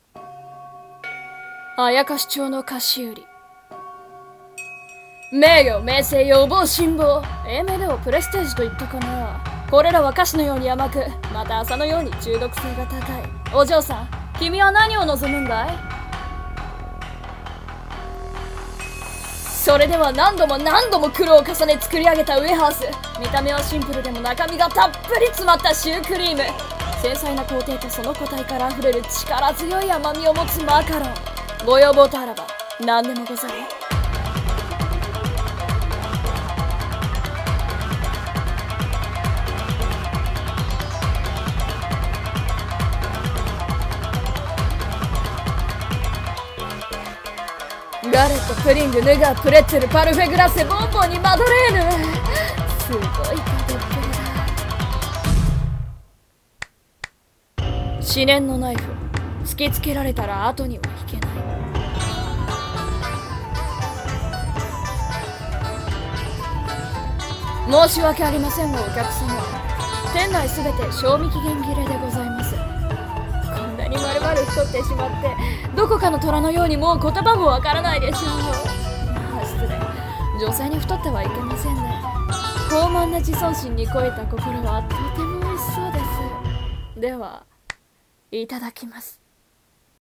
CM風声劇「妖町の菓子売